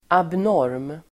Ladda ner uttalet
Uttal: [abn'år:m]
abnorm.mp3